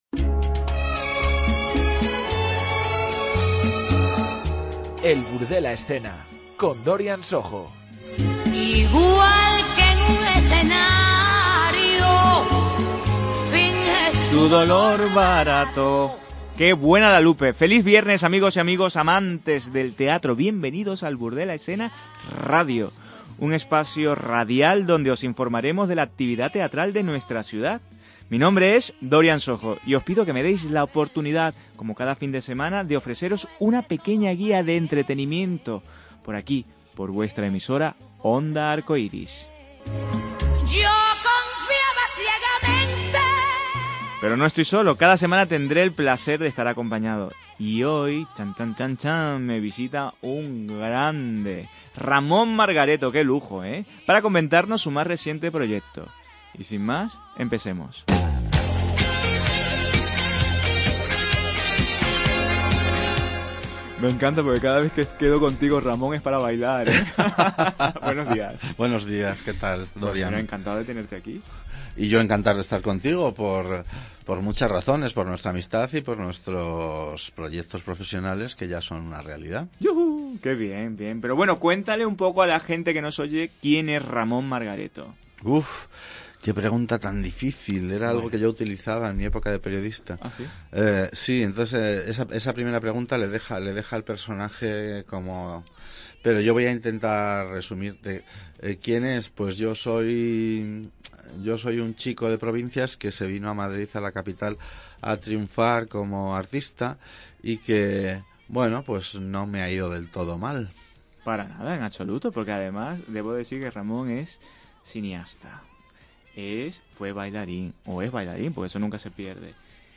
Una entrevista muy personal En El Burdel a Escena